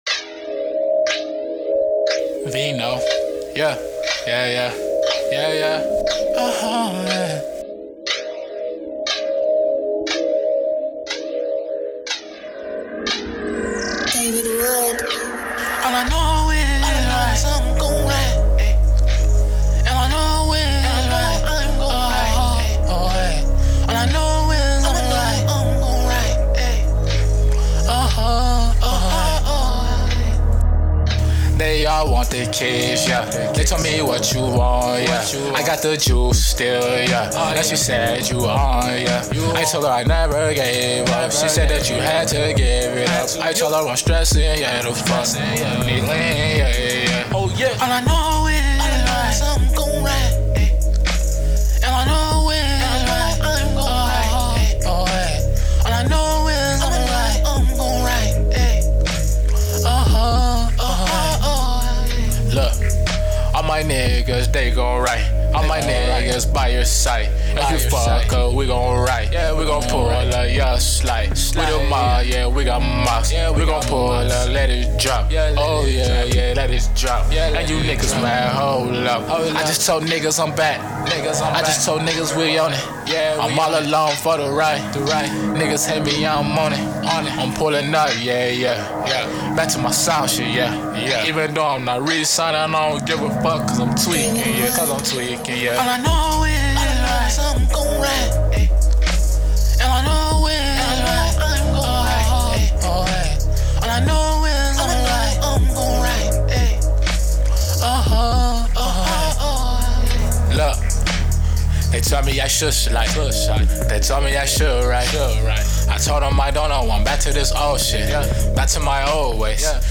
R&B, HipHop, Trap, & Melodic Poject!